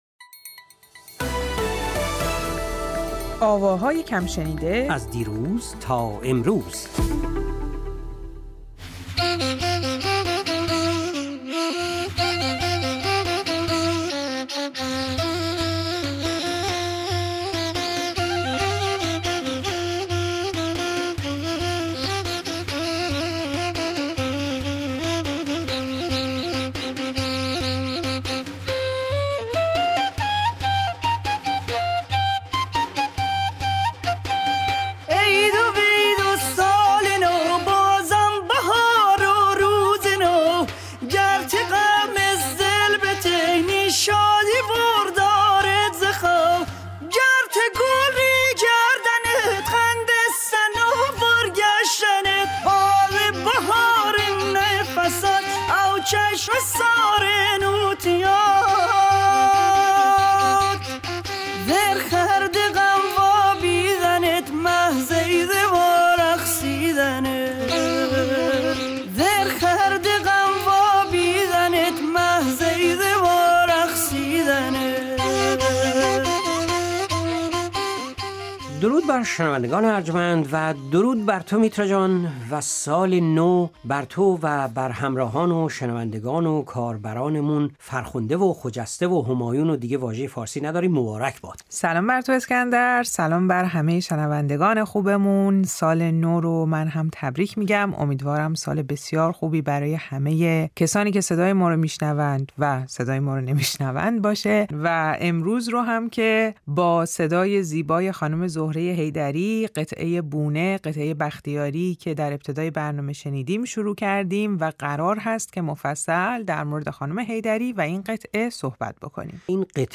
که دارای صدای آلتوی پرقدرتی است